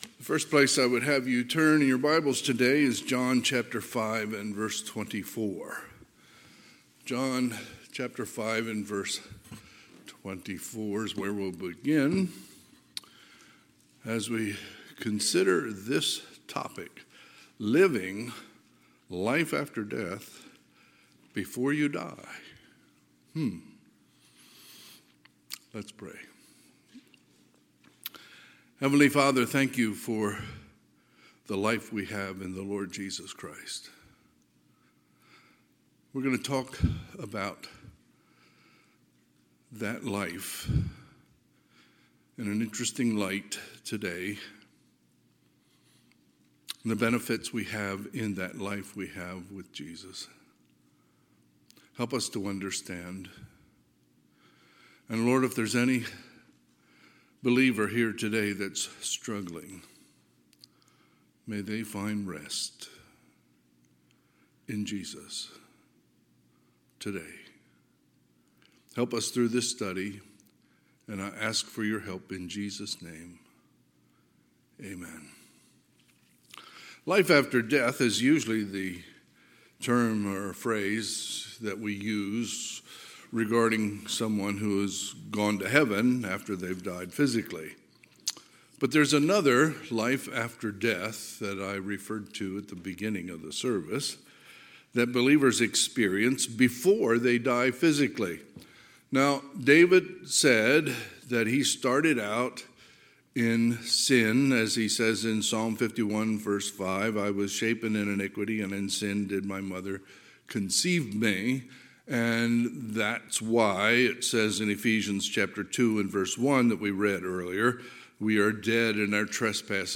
Sunday, February 18, 2023 – Sunday AM
Sermons